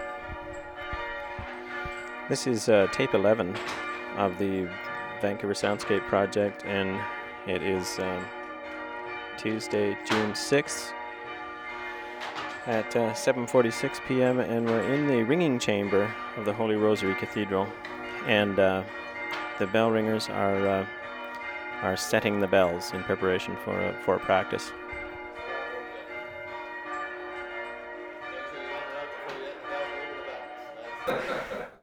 Cathedral Bell Ringing Chamber 0:32
1. tape ID, bells being set in background